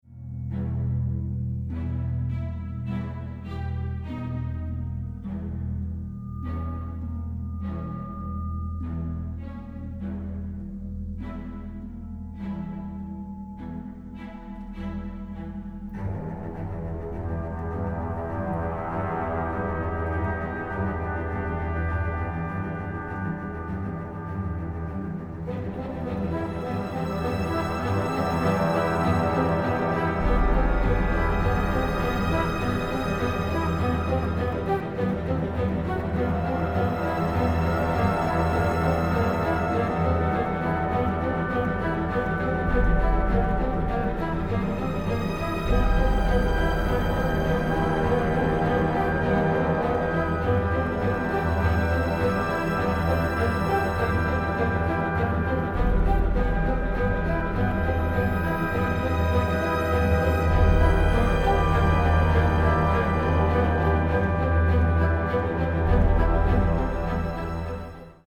an energetic, dark and crude orchestral score